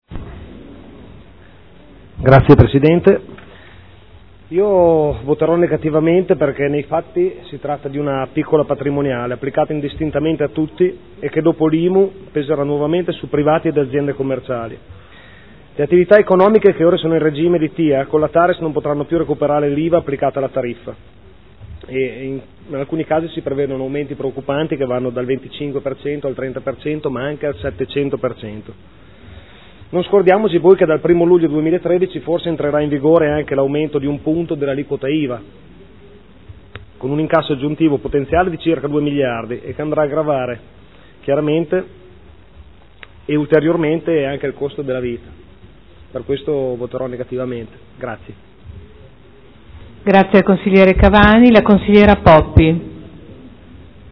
Fabrizio Cavani — Sito Audio Consiglio Comunale
Dichiarazione di voto. Delibera: Tributo comunale sui rifiuti e sui servizi indivisibili – TARES – Approvazione delle tariffe, del Piano Economico Finanziario, del Piano annuale delle attività per l’espletamento dei servizi di gestione dei rifiuti urbani e assimilati